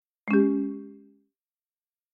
new_message3.mp3